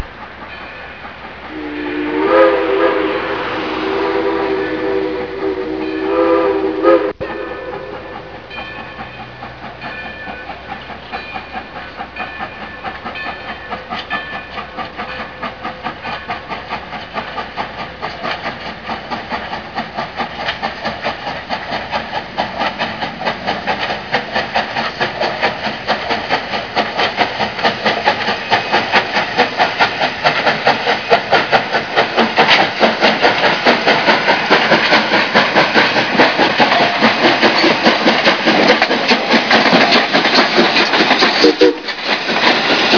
we hear #60 work upgrade with four coaches along side of Pennsylvania Avenue en route to Flemington BRW #60